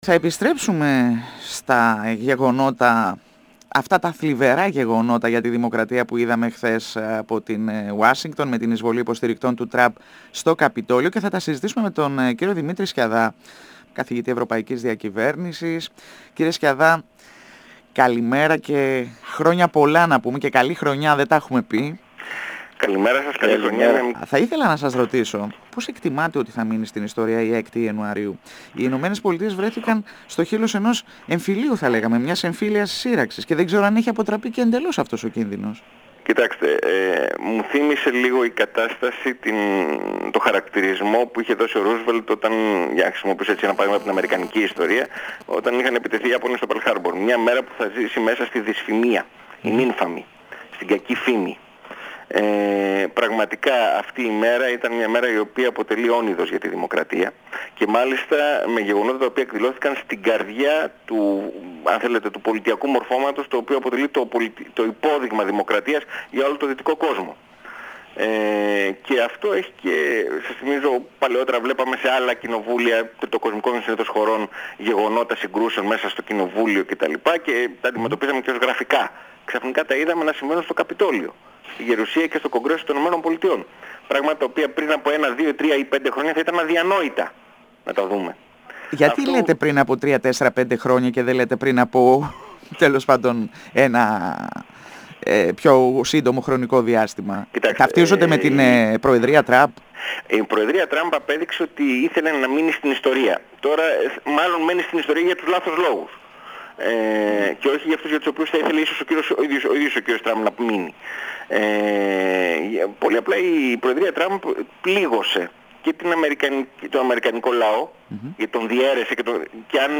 102FM Συνεντεύξεις